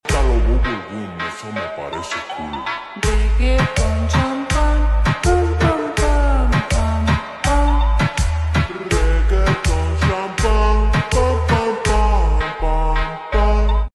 Farting sound effects free download